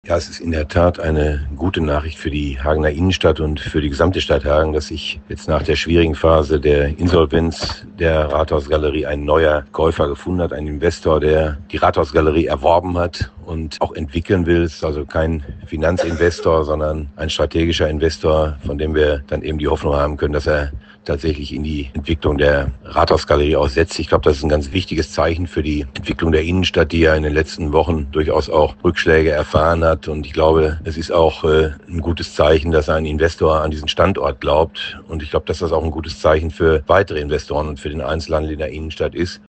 Das bestätigt Oberbürgermeister Erik Schulz im Gespräch mit Radio Hagen.